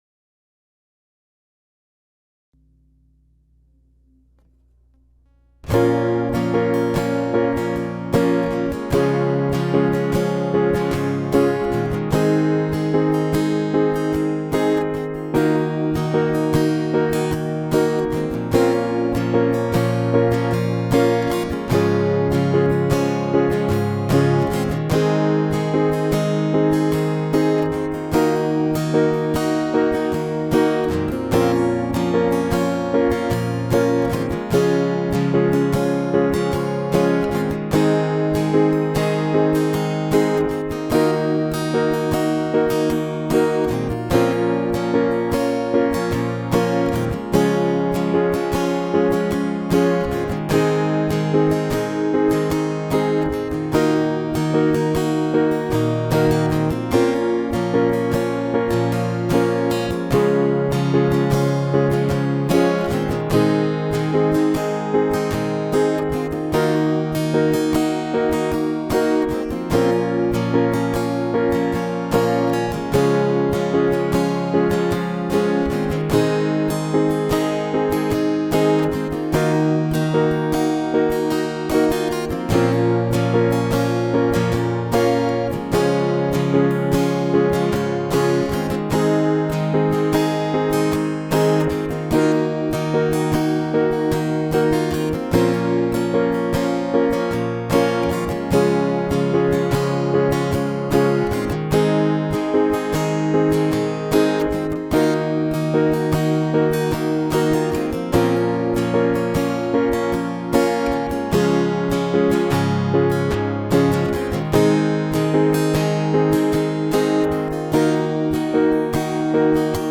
KEFcL7NrLkH_PISTA-ACÚSTICA-PARA-COMPONER-UNA-CANCIÓN---ACOUSTIC-BEAT---FORMA-LIBRE-PIANO---GUITAR.m4a